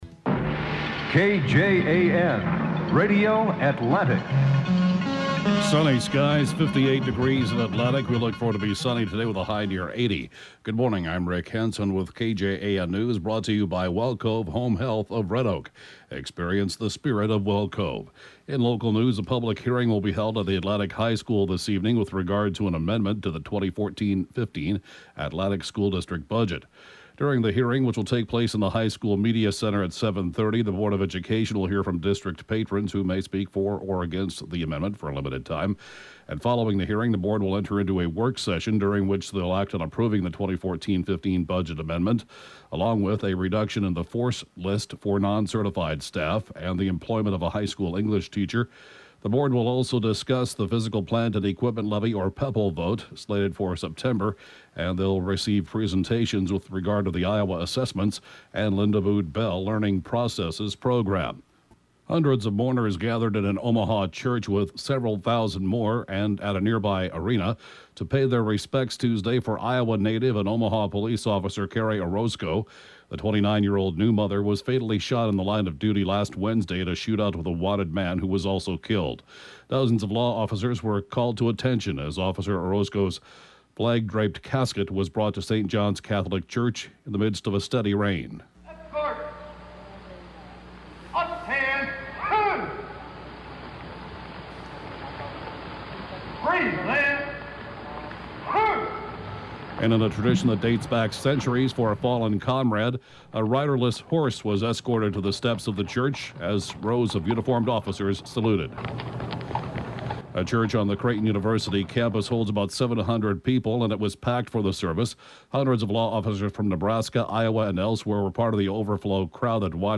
KJAN News can be heard at five minutes after every hour right after Fox News 24 hours a day!